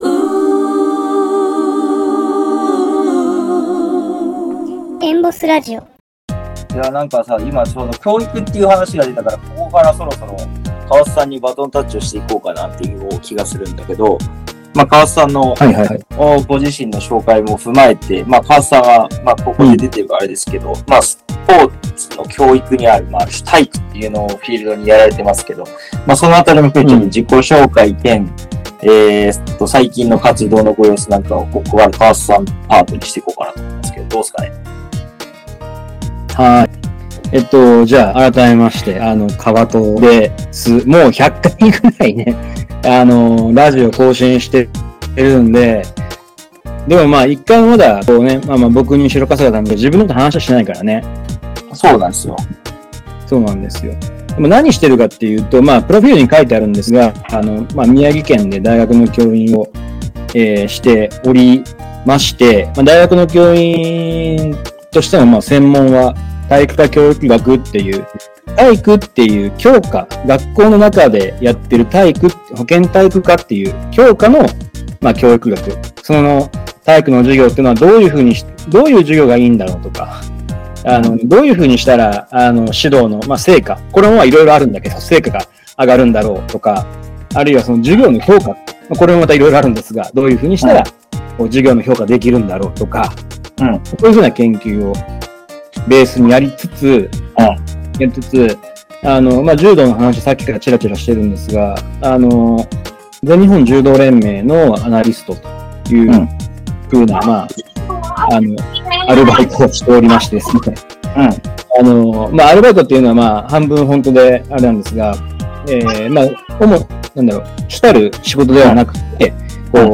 3人で喋っていたら，「どこのシンポジウム？」って感じになりました